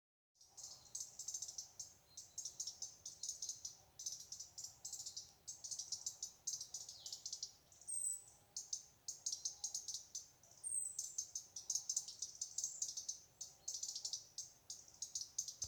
European Robin, Erithacus rubecula
NotesIeraksts- AD-Uztraukuma.